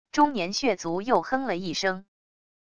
中年血族又哼了一声wav音频